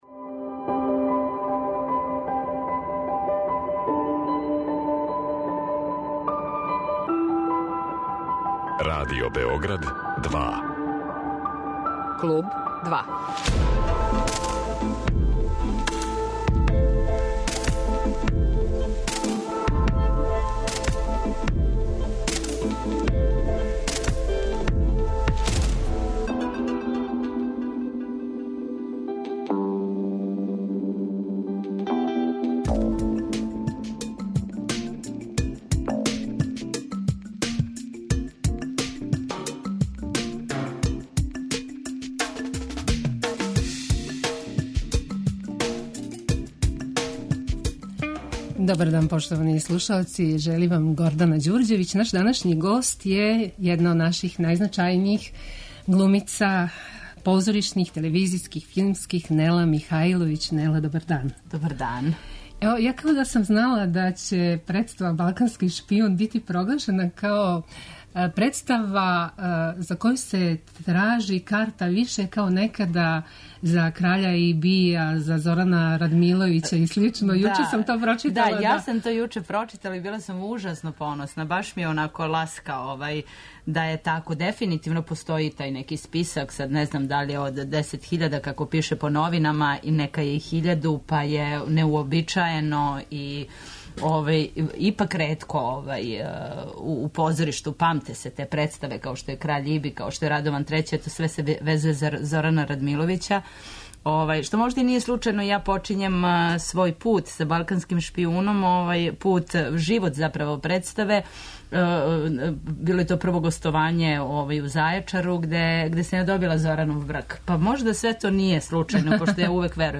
Гост Клуба 2 је једна од најпознатијих позоришних, телевизијских и филмских глумица Нела Михаиловић.